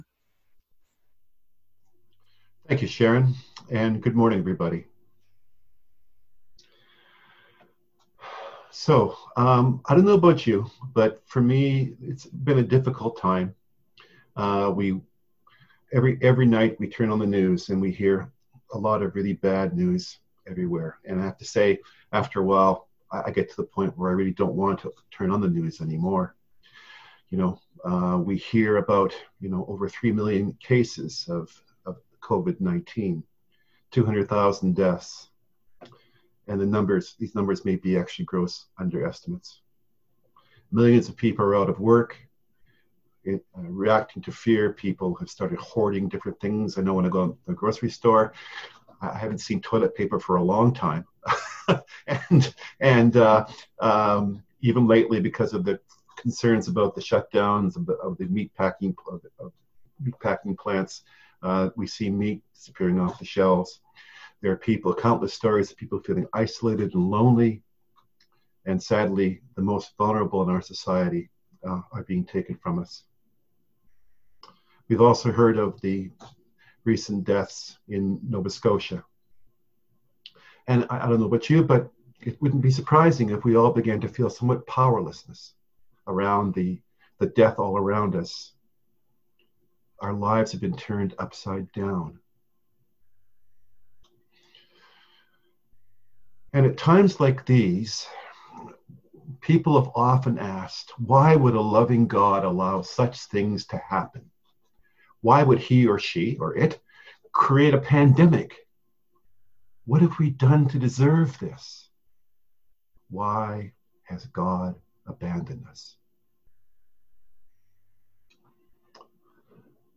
The Power of Creation speaks to how we create our world and what we create. Listen to this inspired address